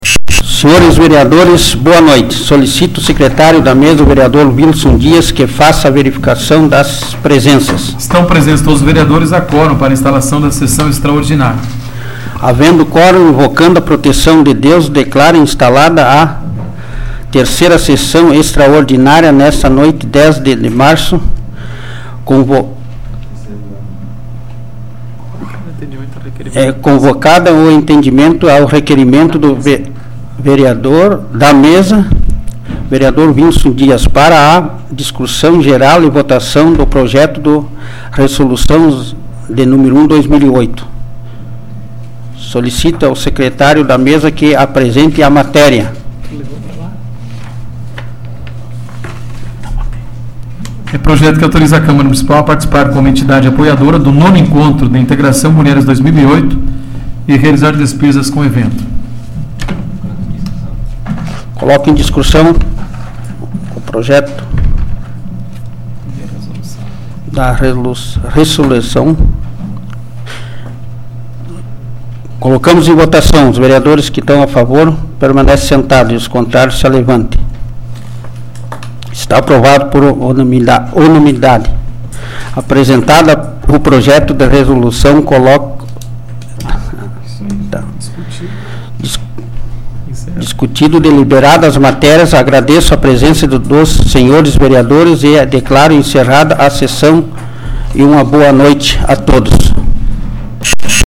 Áudio da 47ª Sessão Plenária Extraordinária da 12ª Legislatura, de 10 de março de 2008